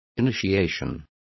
Complete with pronunciation of the translation of initiations.